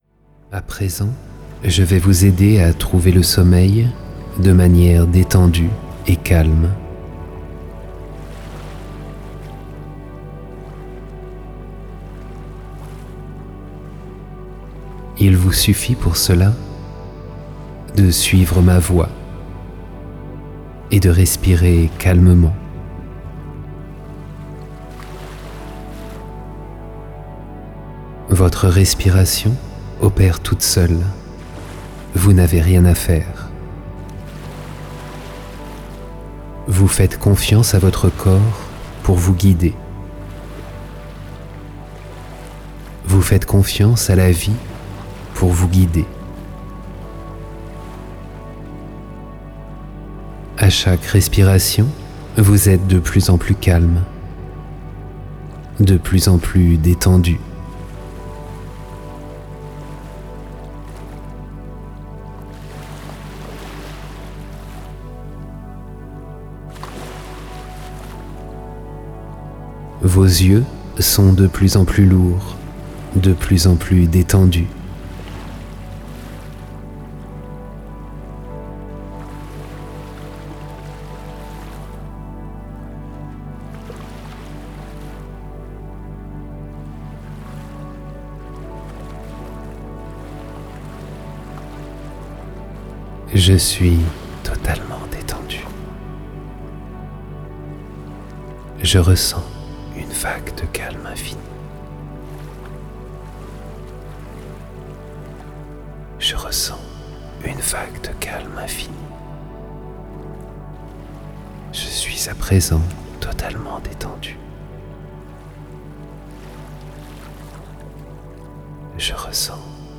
Hypnose méditative – Apprendre à s'endormir - Ce livre audio vous propose six exercices simples et efficaces pour trouver le sommeil et accroître votre bien-...
Grâce à une combinaison de suggestions et de mondes sonores apaisants, vous ferez l'expérience d'une régénération physique et mentale remarquable.